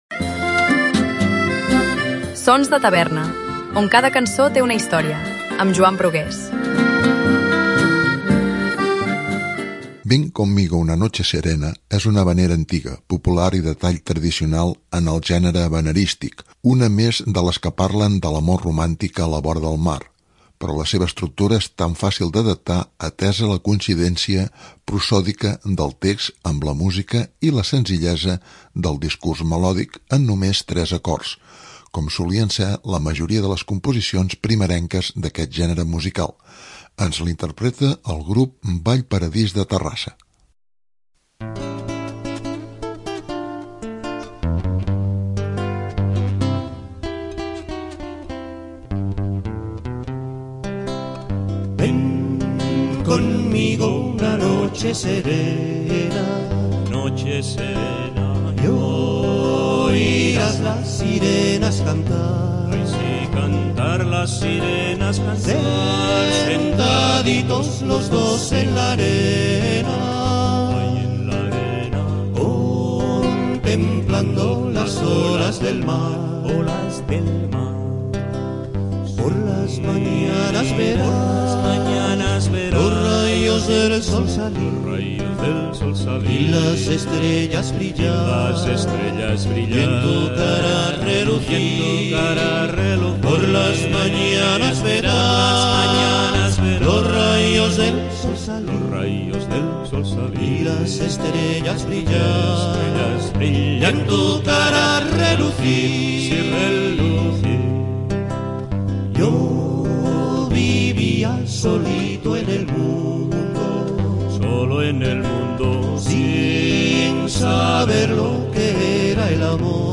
Ven conmigo una noche serena, és una havanera antiga , popular i de tall tradicional en el gènere havaneristic. Una més de les que parlen de l'amor romàntic a la vora del mar, però la seva estructura és fàcil d'adaptar atesa la coincidència prosòdica del text amb la música i la senzillesa del discurs melòdic, amb només 3 acords. com solien ser la majoria de les componsions primerenques d’aquest gènere musical. Ens la interpreta el grup Vallparadís de Terrassa.